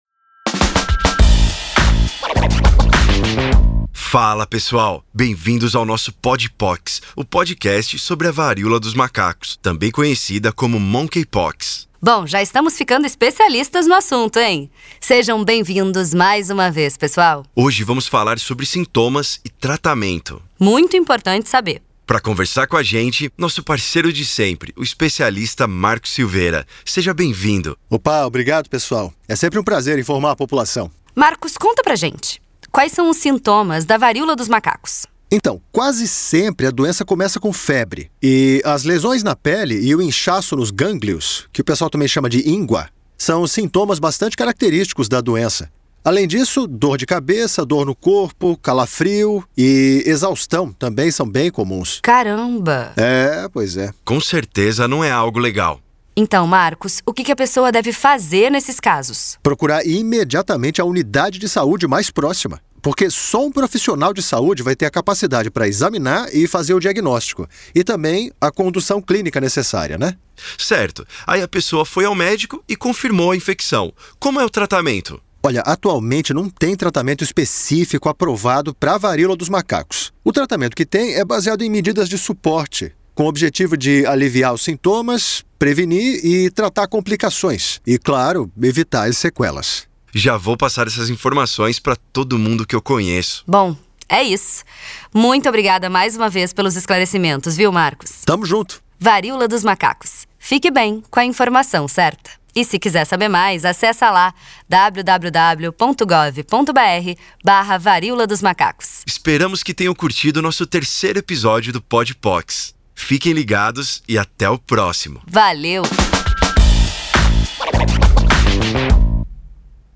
Spot - PodPox Episódio 03 .mp3